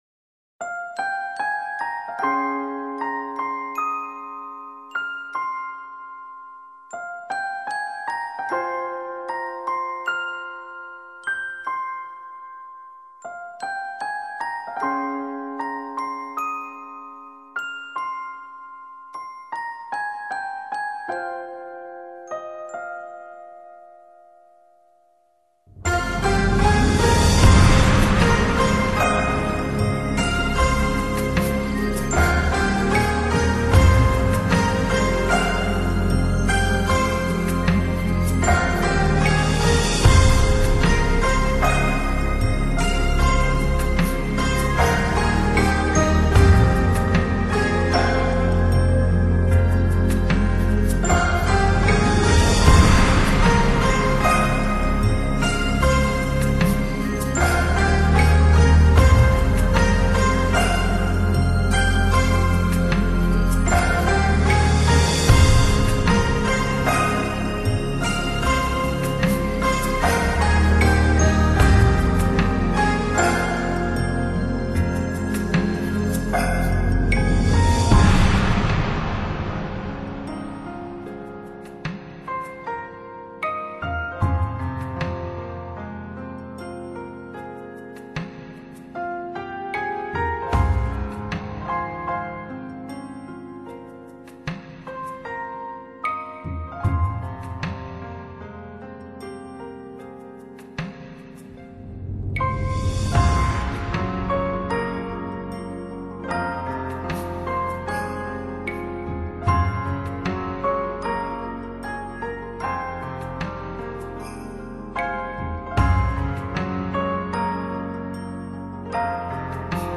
有点中国风古典元素